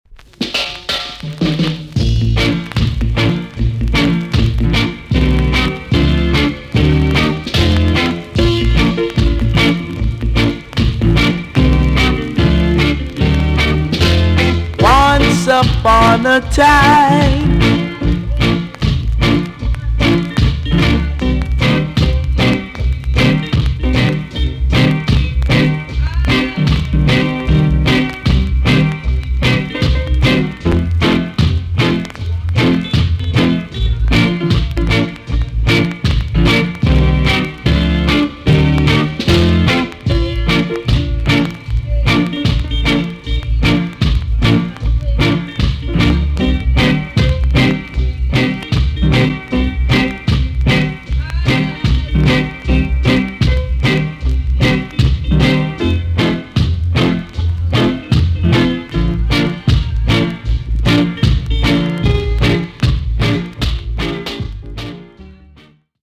TOP >REGGAE & ROOTS
B.SIDE Version
VG+~VG ok 軽いチリノイズが入ります。